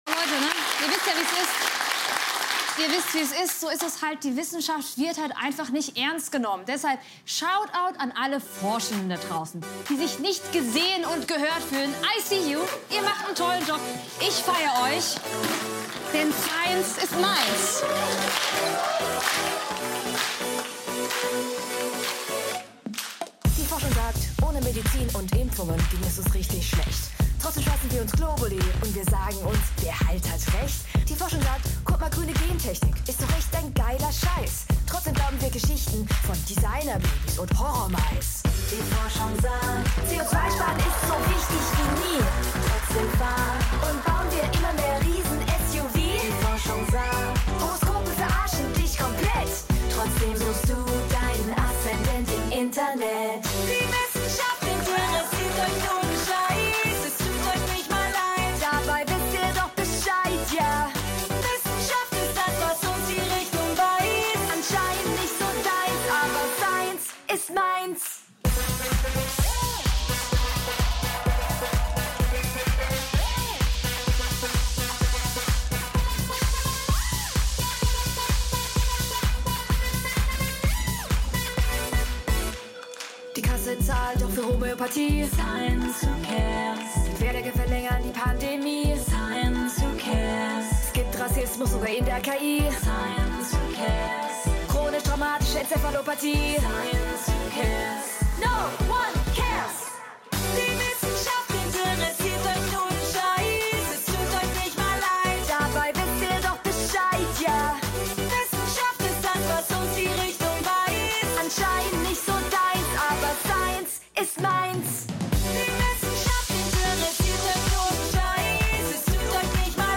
Daher hier ein Lied für alle Forschenden, die nicht gesehen werden.